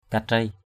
/ka-treɪ/ (d.) cái kéo. mâk katrei cakak aw mK kt] ckK a| lấy kéo cắt áo.